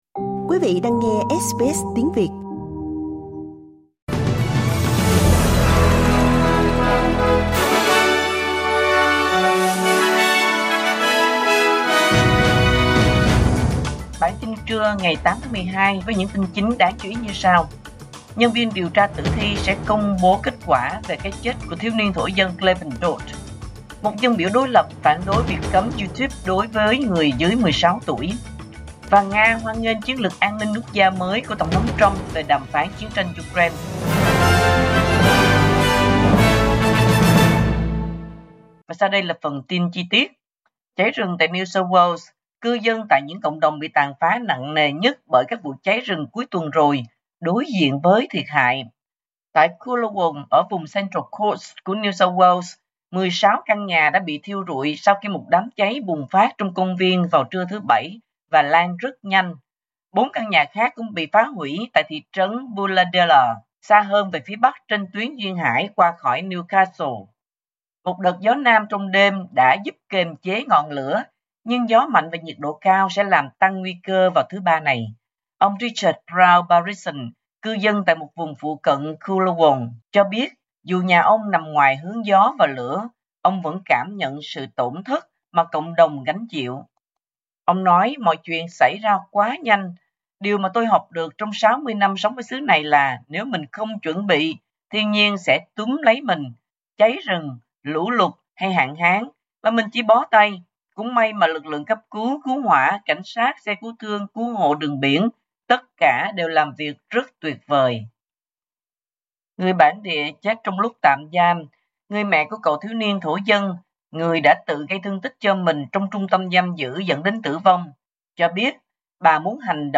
Vietnamese news bulletin Source: Getty